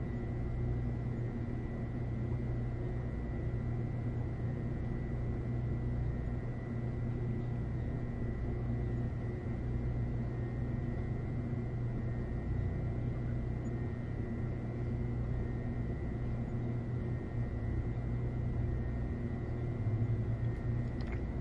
描述：我不确定这个是如何产生的，但我认为这是一个模拟的2"小号录音带在混音过程中颠倒的末端
标签： 卷轴 划痕 小鼓 类似物 磁带 击鼓声 逆转滚筒
声道立体声